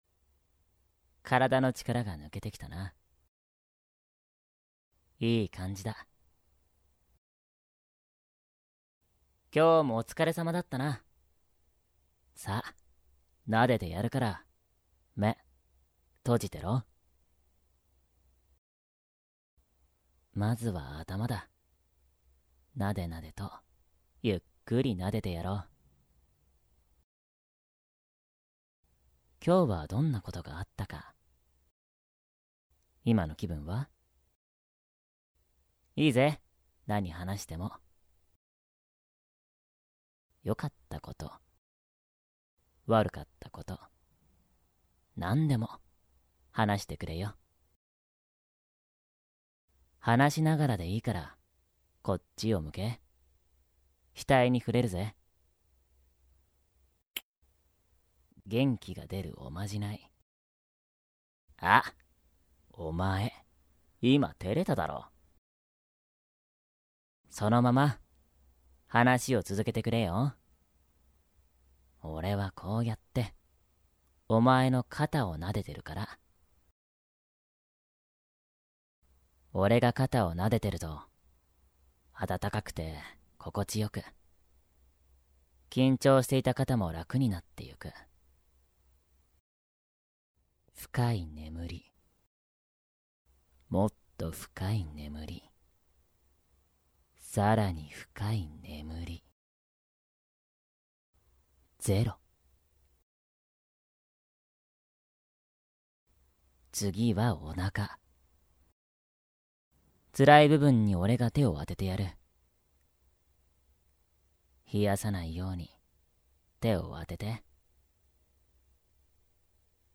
催眠音声
02-03_（元氣系）溫柔撫摸_女孩子之日.mp3